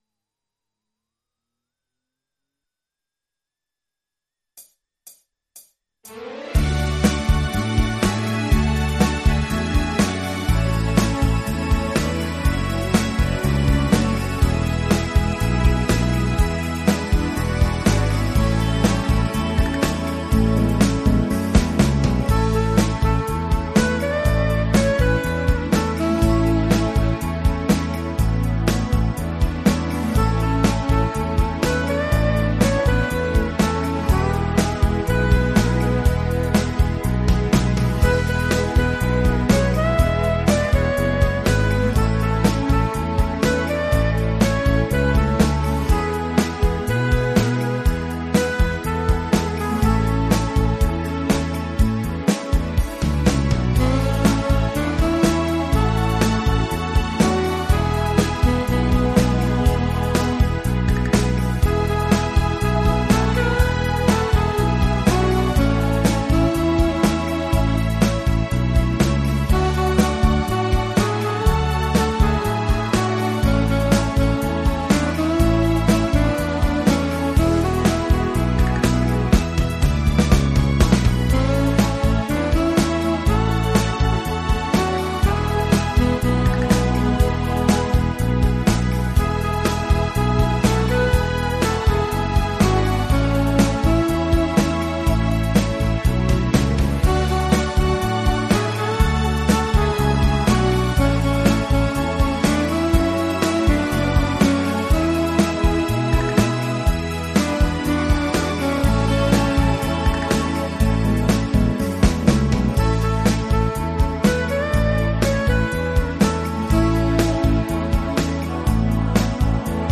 multi-track instrumentale versie